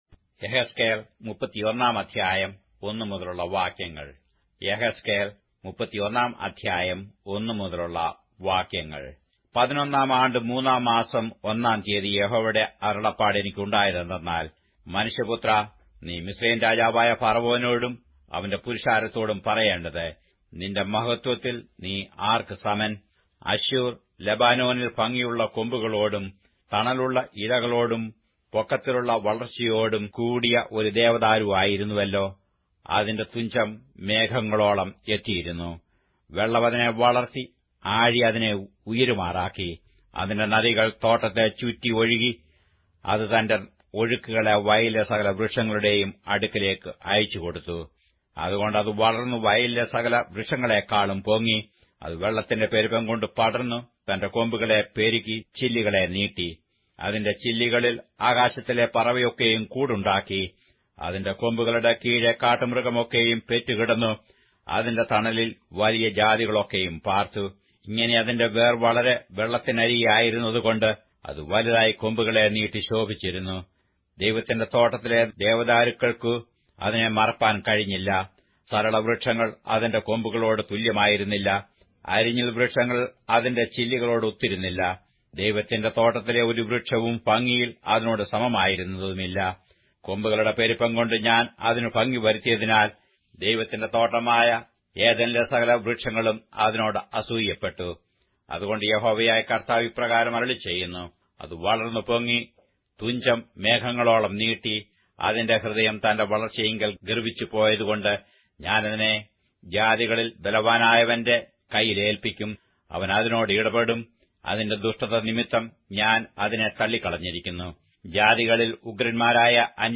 Malayalam Audio Bible - Ezekiel 48 in Irvor bible version